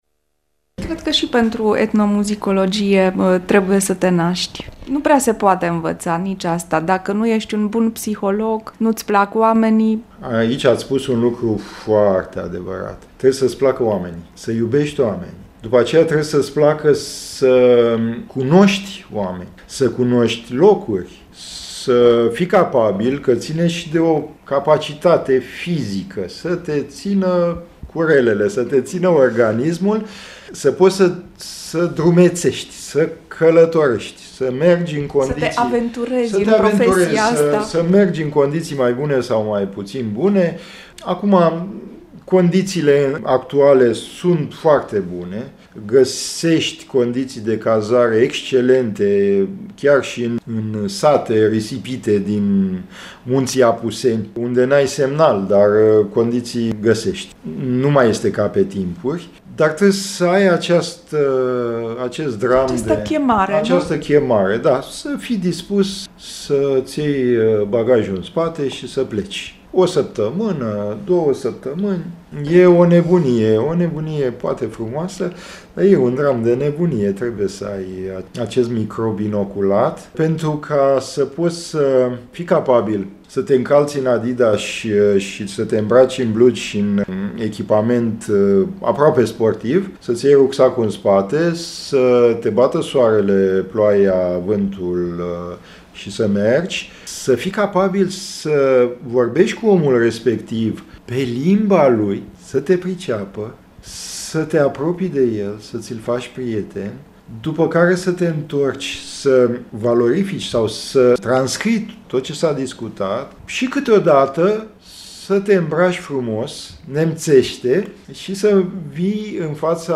va rosti la microfonul nostru cele mai minunate gânduri despre… “Menirea etnomuzicologului”.